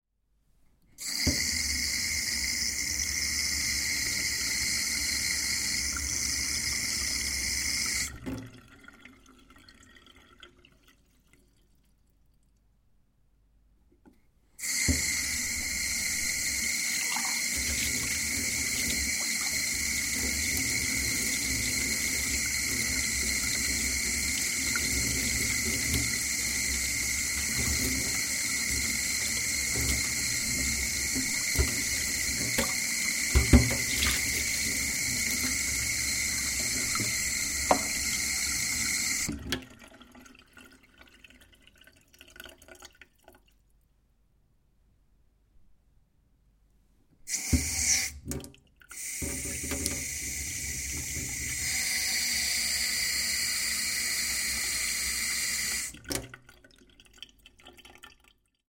Water » Turning a Tap Off
描述：Turning a tap off and hearing the water gargle down the drain. Recorded on a Zoom H1.
标签： off Turn water tap drain gargle
声道立体声